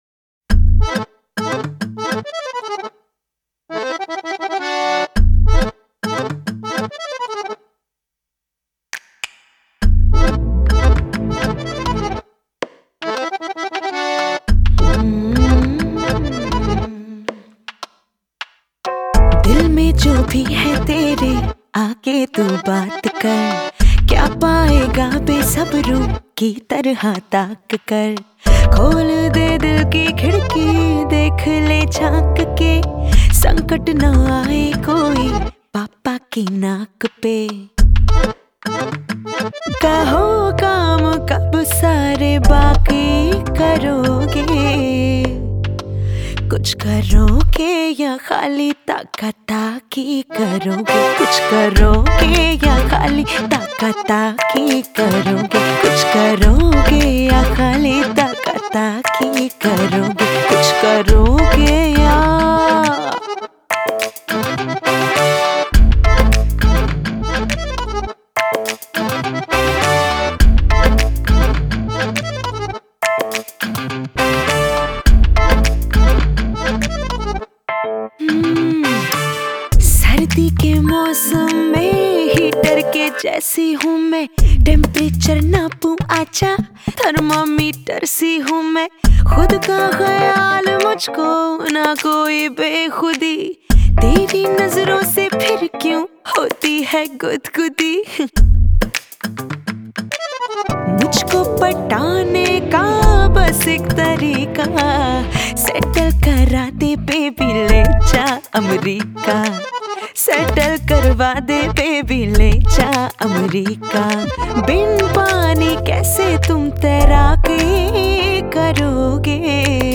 Category: Bollywood